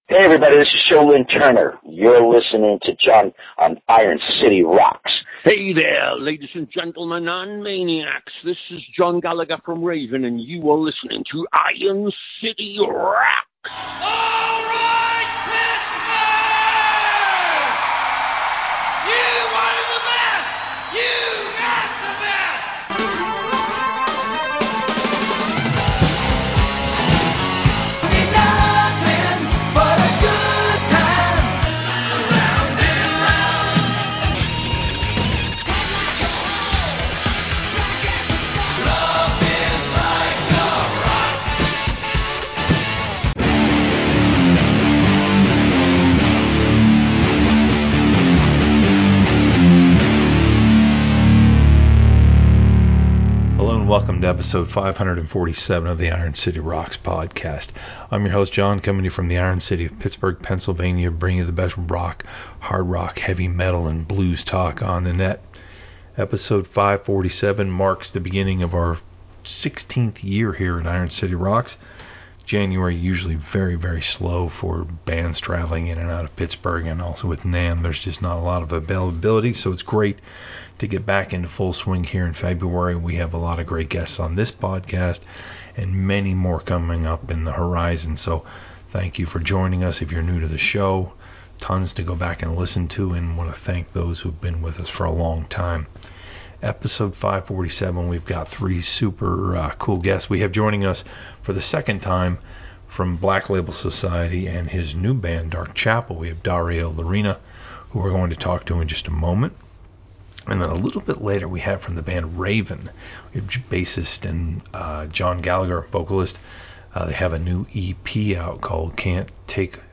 Coming to you from the "Iron City" of Pittsburgh, Pennsylvania, the Iron City Rocks Podcast brings you the best talk and interviews with the best in rock, heavy metal, blues and hard rock music.